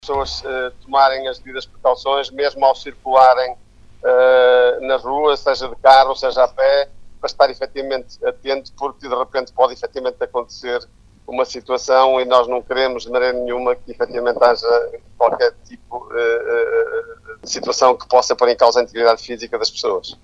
Vítor Marques sublinha que a Proteção Civil está no terreno a dar resposta a várias ocorrências e tranquiliza a população: prevê-se uma acalmia do estado do tempo nas próximas horas.